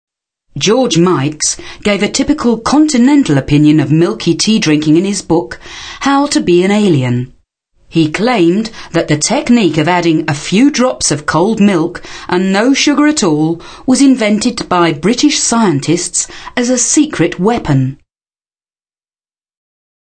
englisch (uk)
mid-atlantic
Sprechprobe: Industrie (Muttersprache):
female voice over artist german